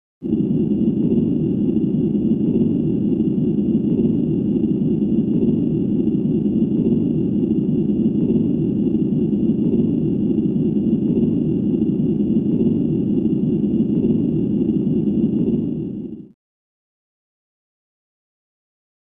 Industrial Plant Ambience, Low Frequency, Steady.